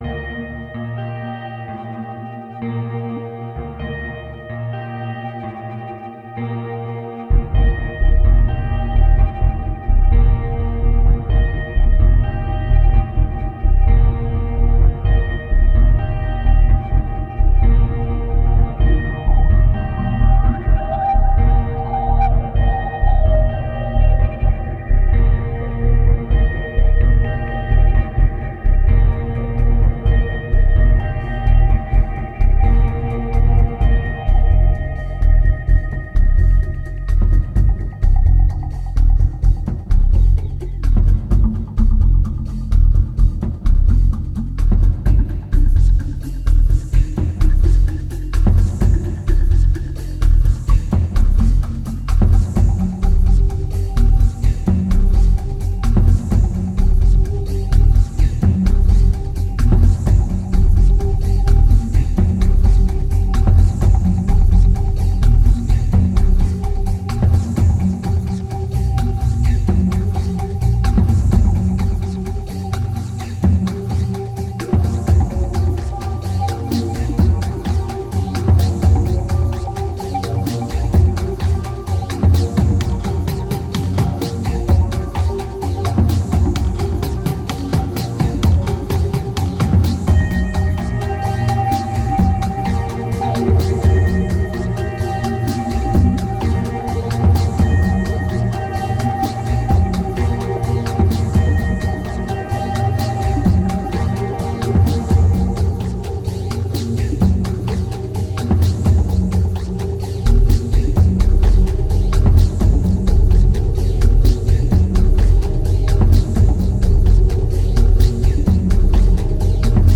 2258📈 - -24%🤔 - 128BPM🔊 - 2010-11-27📅 - -240🌟